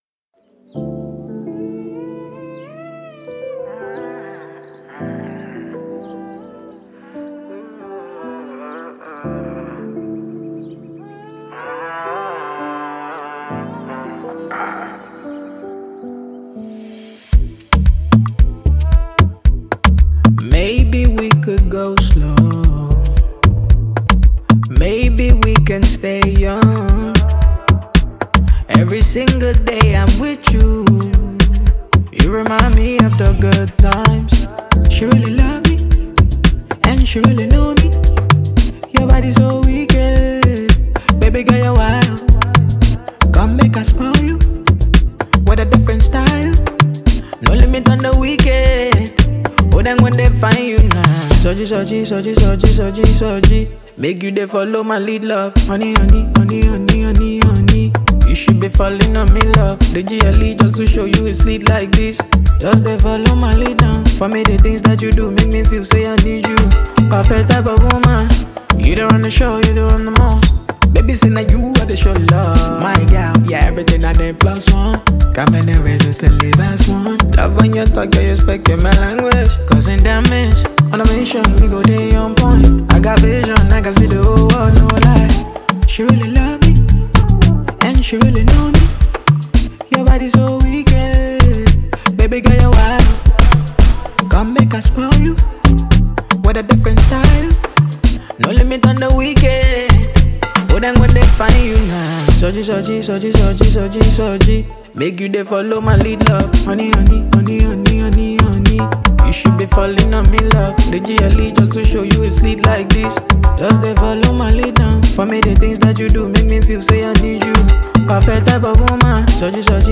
Grammy Award winning Nigerian heavyweight Afrobeat Singer
studio Album